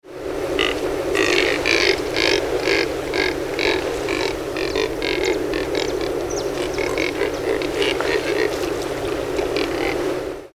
Nannopterum brasilianum
Nome em Inglês: Neotropic Cormorant
Aprecie o canto do
Biguá